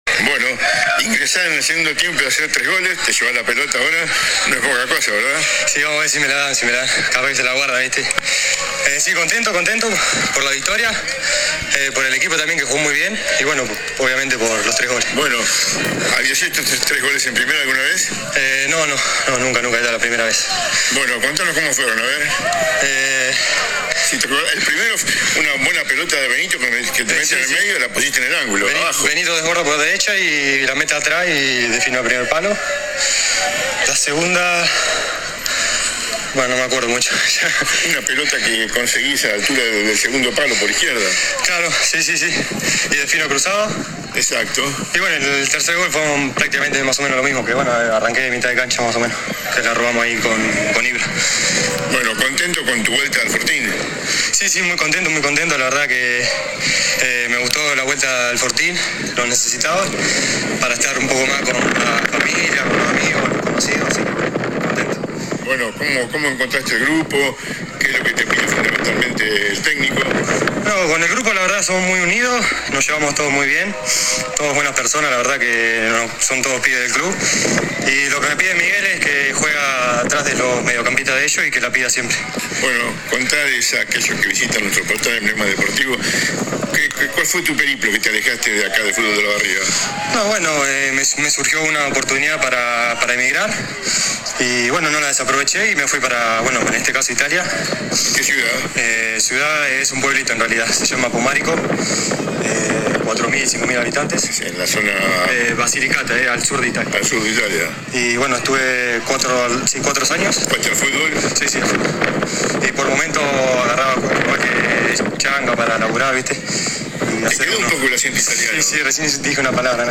Finalizado el cotejo del domingo, dialogamos con el joven artillero, para nuestro portal.
AUDIO DE LA ENTREVISTA